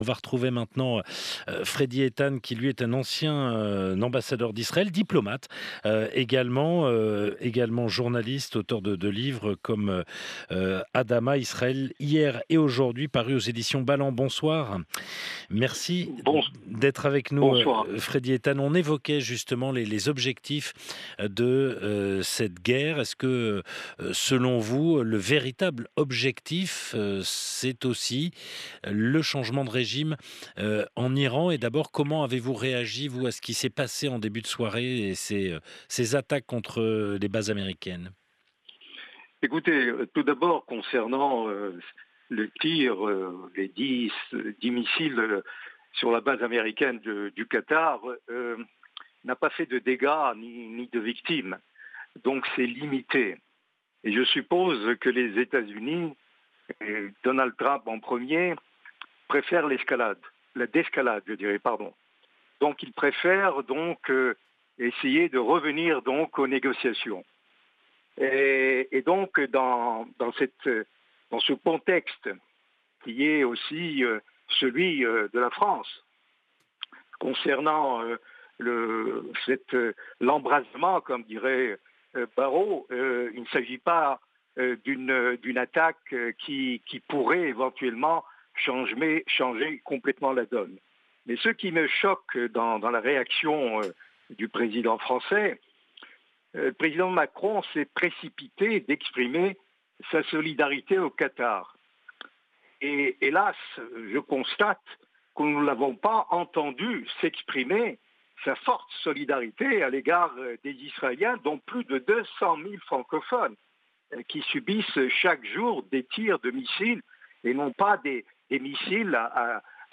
Escalade ou d'escalade? Interview - Le CAPE de Jérusalem